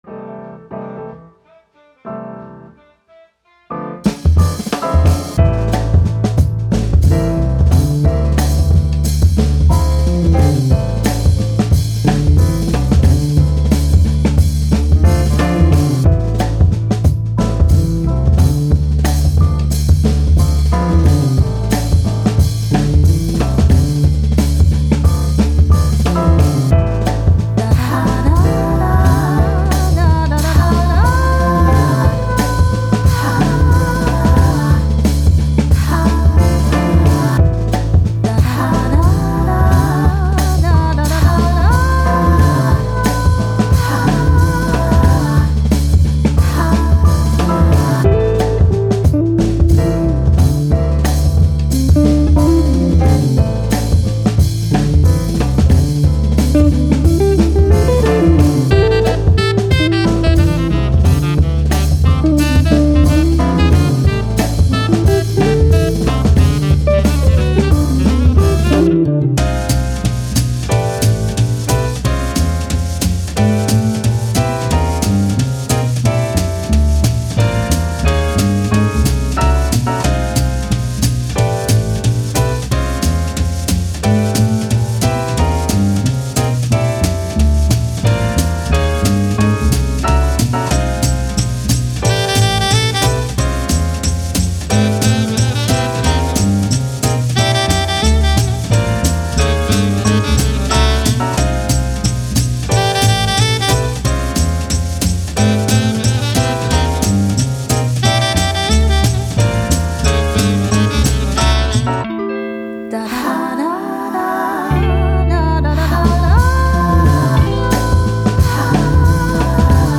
Jazz, Upbeat, Action, Vocal